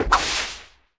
Block Lava Sound Effect
block-lava.mp3